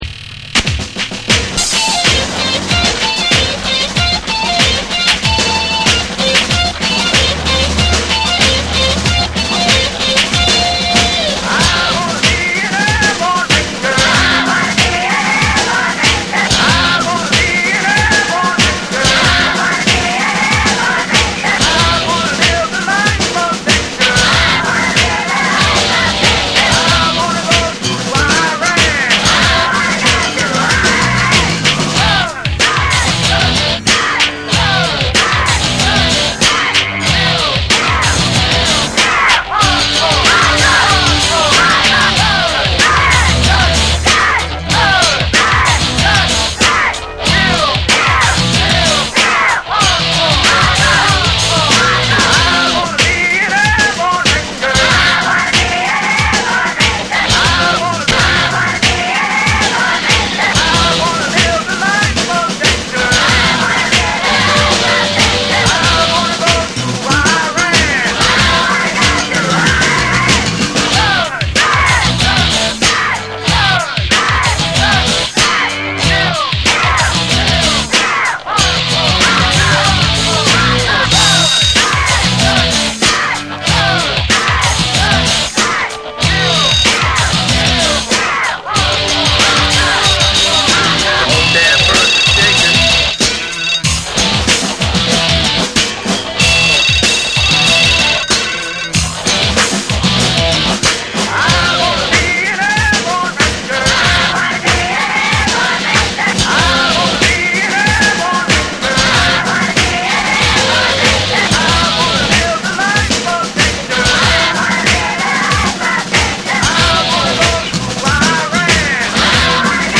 ファンク、ヒップホップ、ダブを強烈にミクスチャーし、鋭い社会性と実験性を兼ね備えた傑作。
BREAKBEATS / REGGAE & DUB